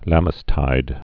(lăməs-tīd)